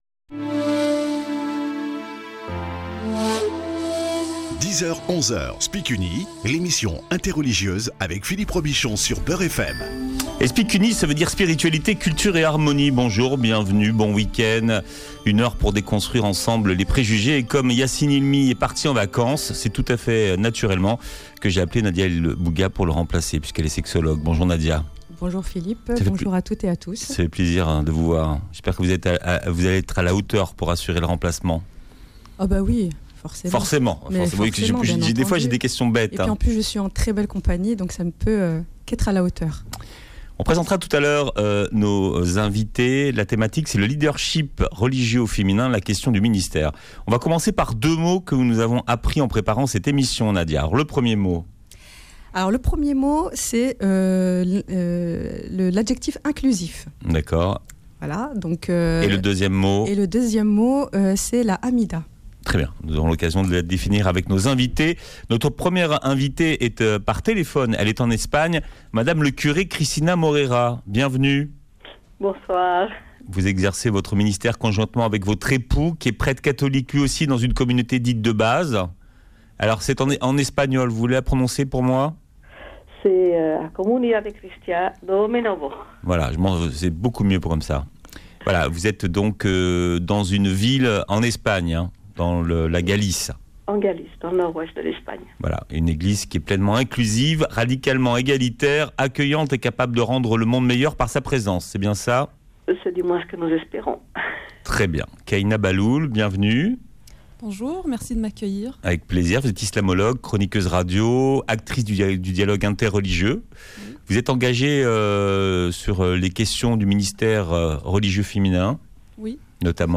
était avec nous par liaison téléphonique.